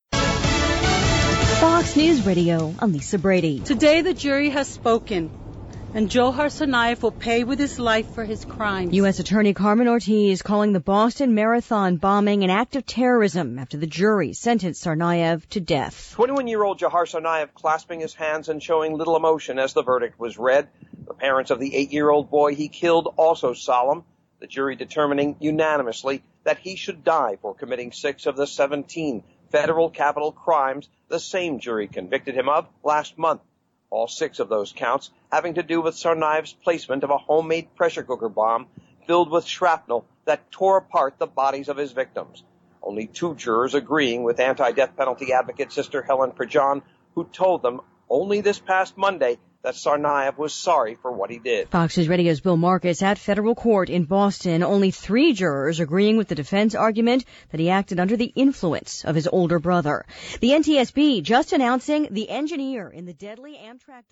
LIVE 6PM –